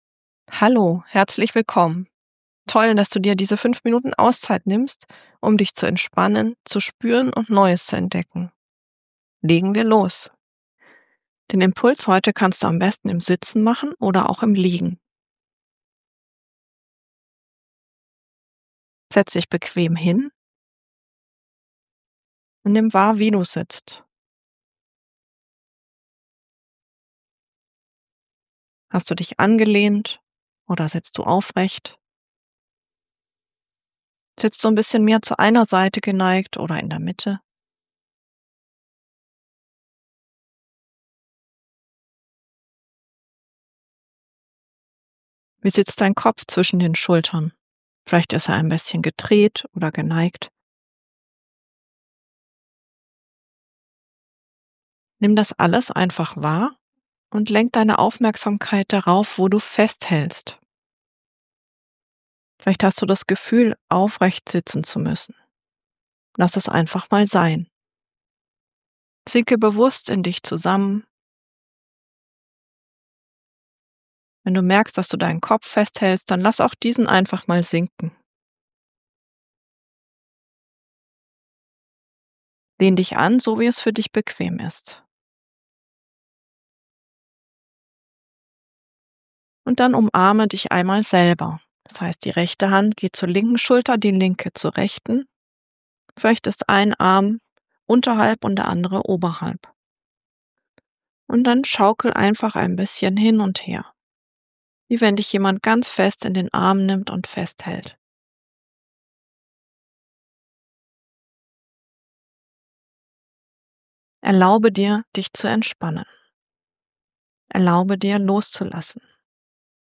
Hier findest Du das Entspannung-Audio von heute: